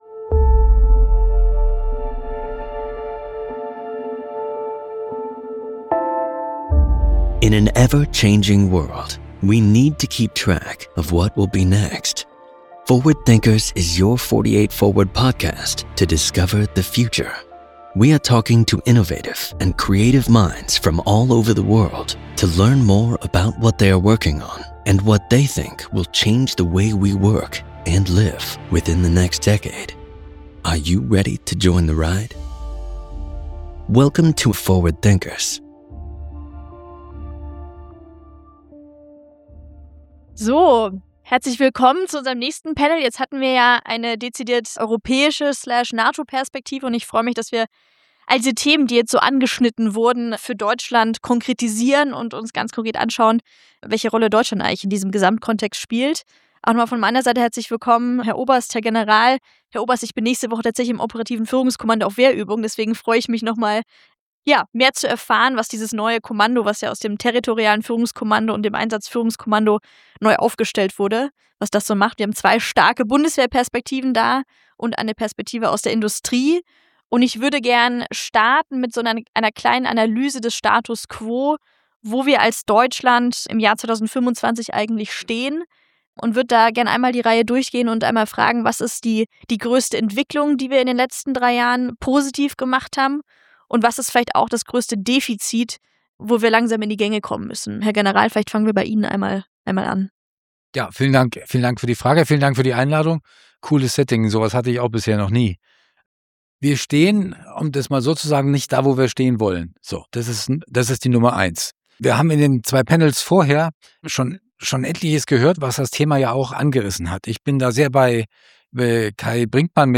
Ein Gespräch über Abschreckung, Beschaffung – und die Frage, was dieses Land eigentlich verteidigen will.